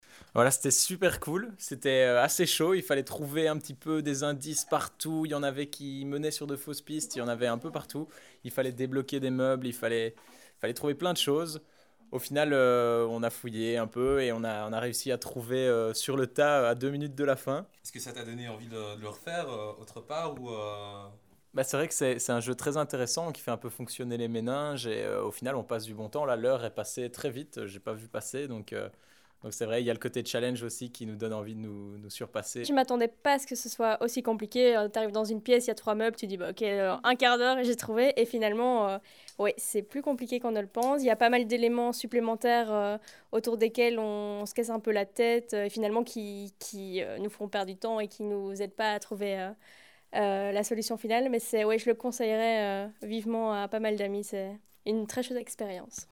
Escape-réactions.mp3